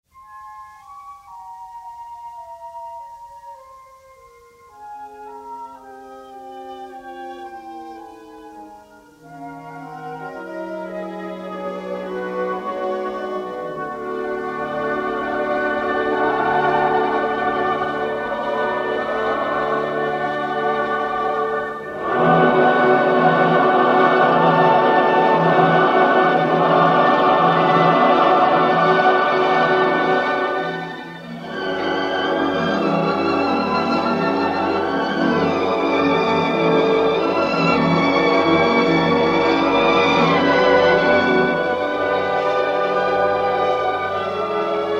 a gorgeous score brimming with sympathy and melancholy.
The sound quality is largely "archival,"